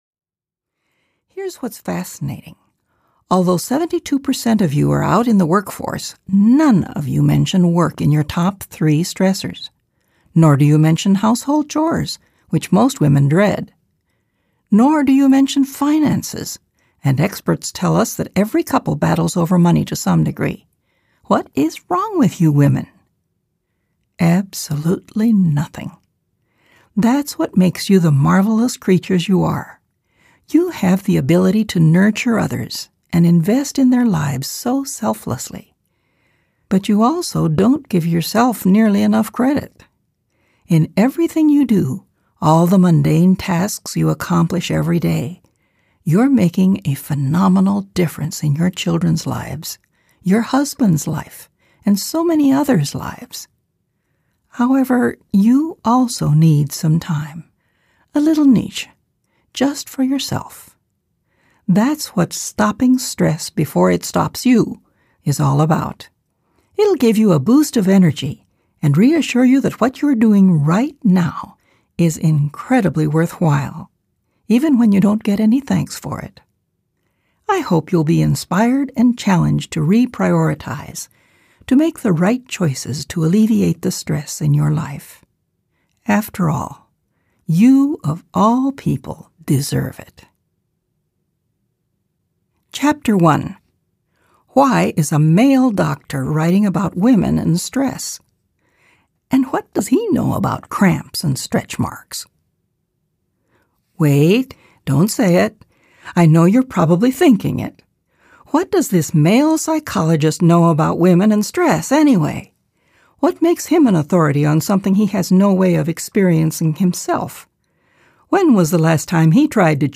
Narrator
5.75 Hrs. – Unabridged